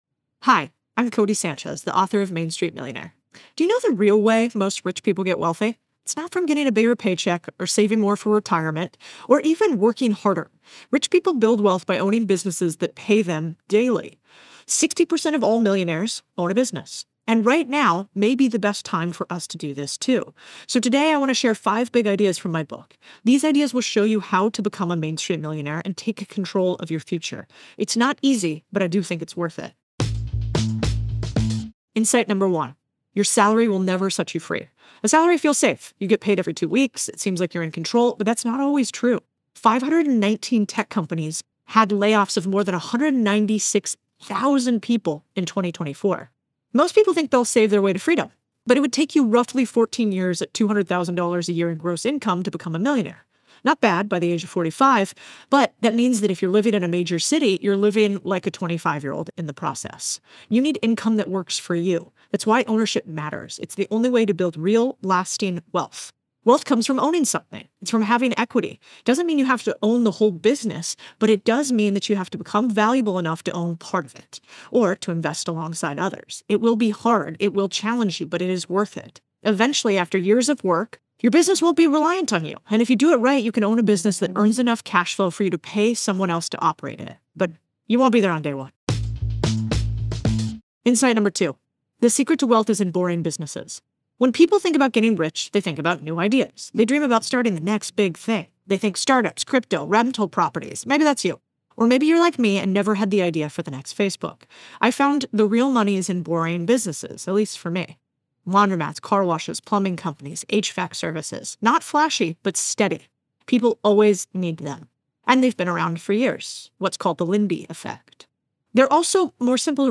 Below, Codie shares five key insights from her new book, Main Street Millionaire: How to Make Extraordinary Wealth Buying Ordinary Businesses. Listen to the audio version—read by Codie herself—in the Next Big Idea App.